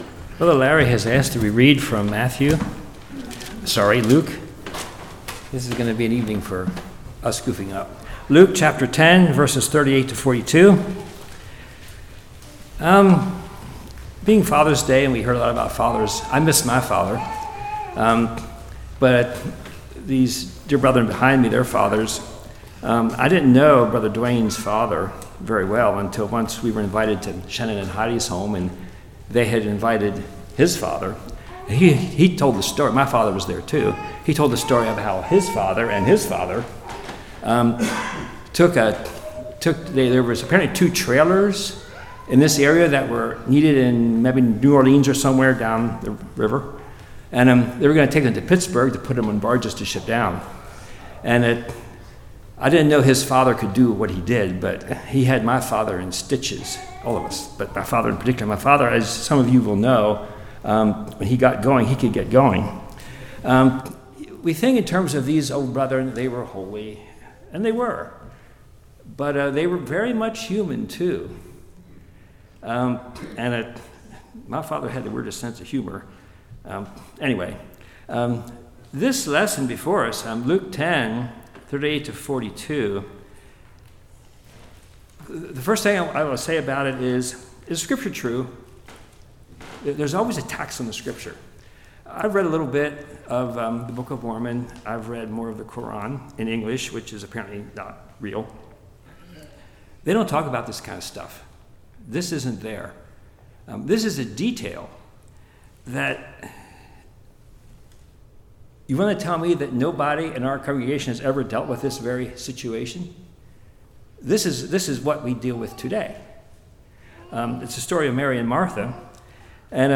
Luke 10:38-42 Service Type: Evening Blind spots